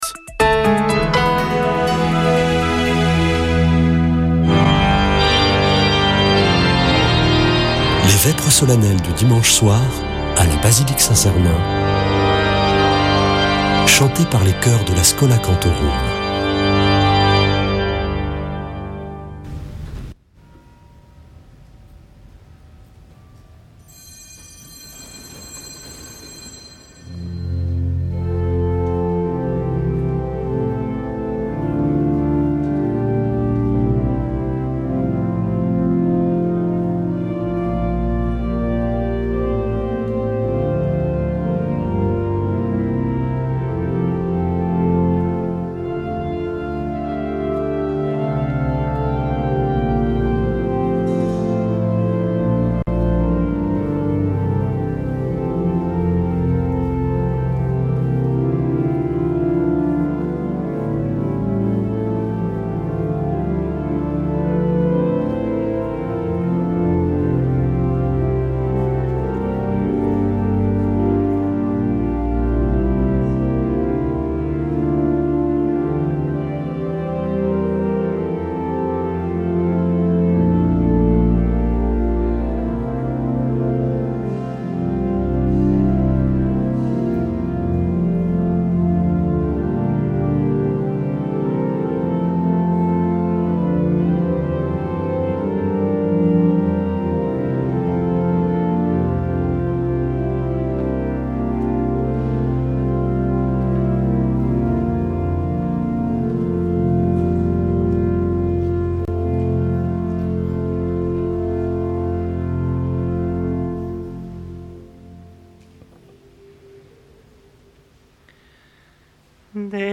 Vêpres de Saint Sernin du 02 nov.
Une émission présentée par Schola Saint Sernin Chanteurs